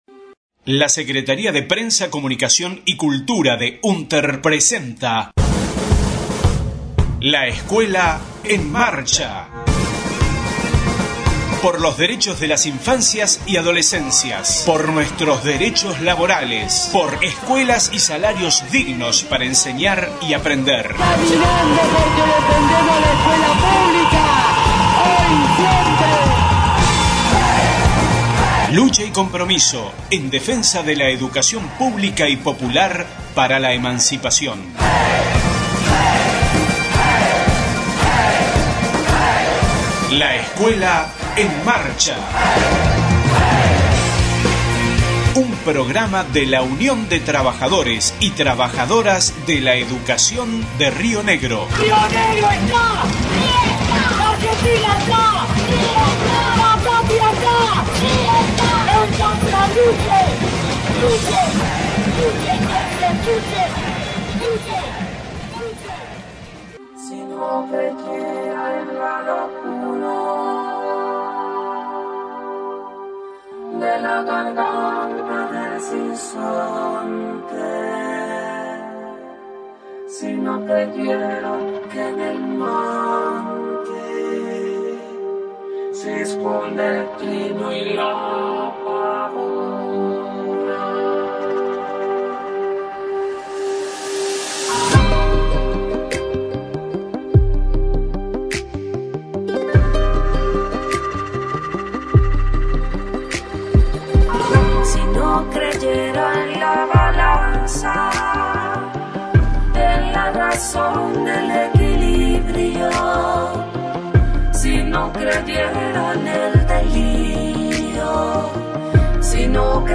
LEEM, 11/09/20: 11 de septiembre día dxl maestrx. Saludo de Secretarixs Generales.